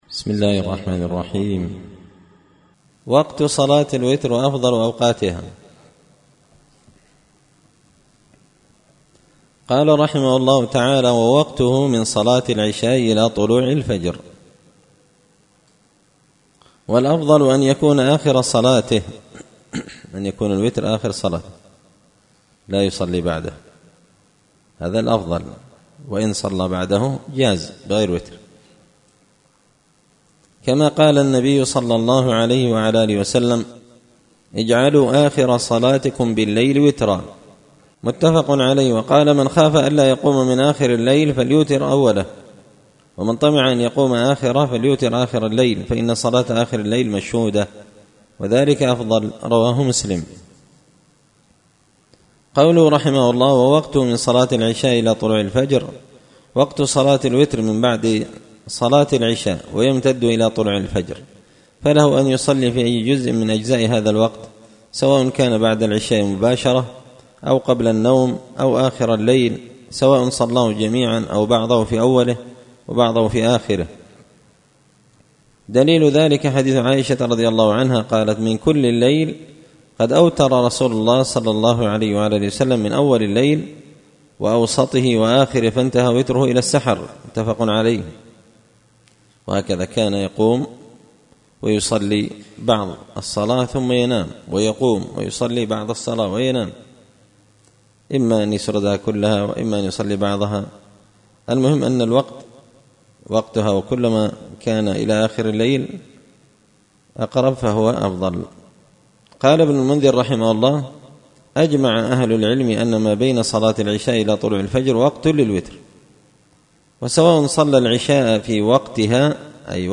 منتقى الأفنان في فقه الصوم وأعمال رمضان الدرس الثامن عشر
دار الحديث بمسجد الفرقان ـ قشن ـ المهرة ـ اليمن